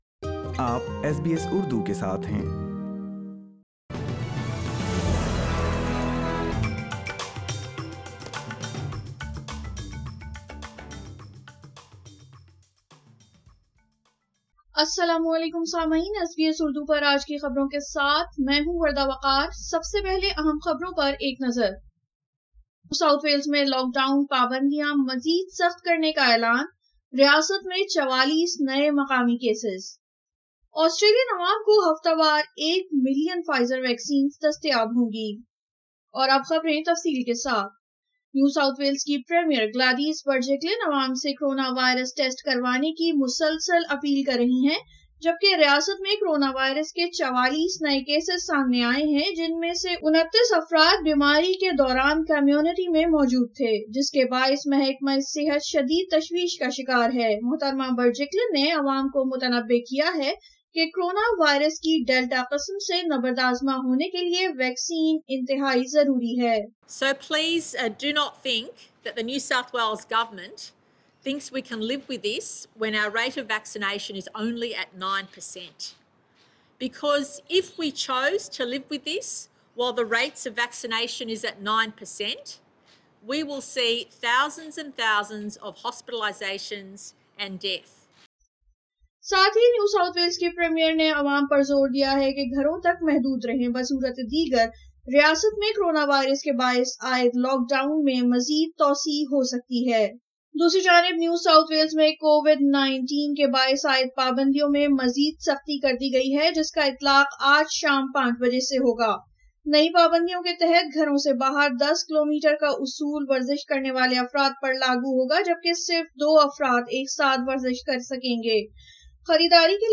SBS urdu News 09 July 2021